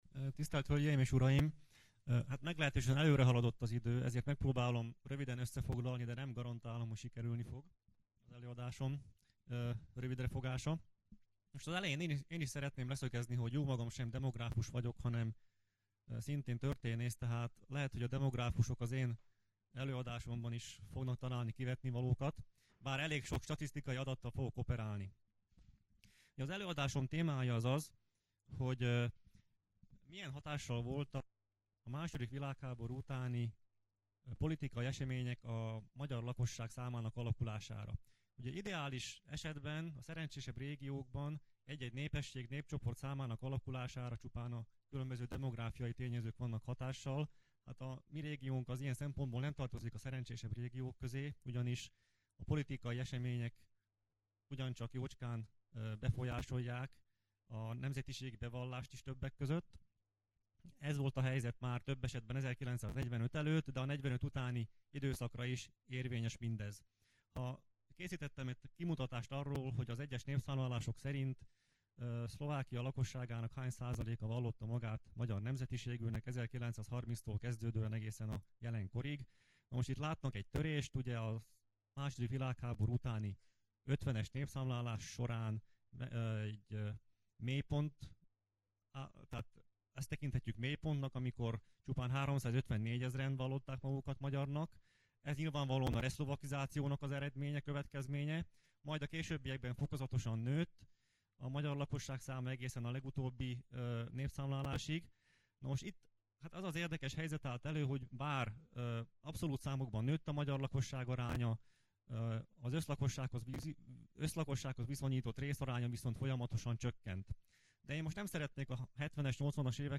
Konferenciák, rendezvények hangfelvételei
Somorja, Somorja (Šamorín), Fórum Intézet (Fórum inštitút), konferenciaterem (konferencčná sála)
Nemzetközi konferencia